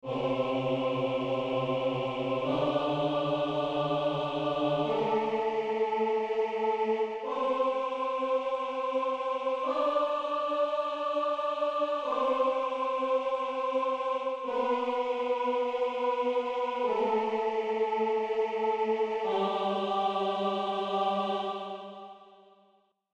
En-octavas.mp3